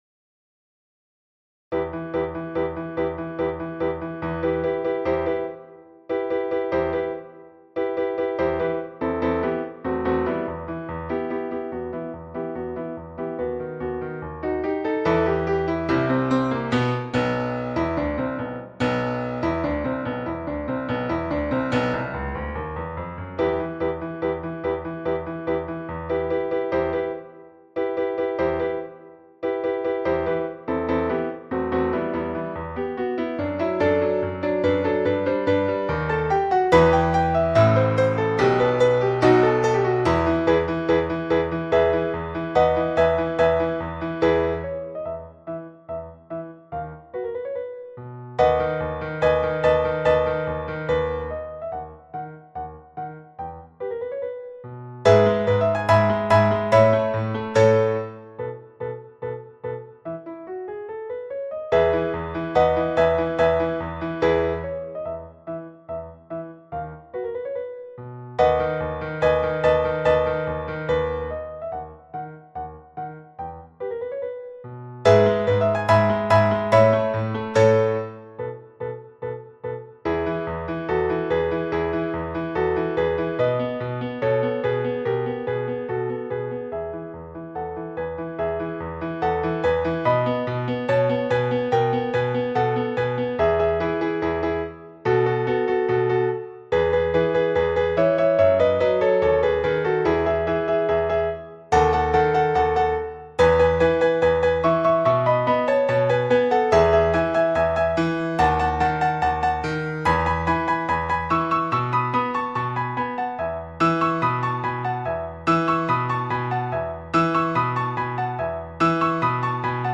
The Barber of Seville – piano à 144 bpm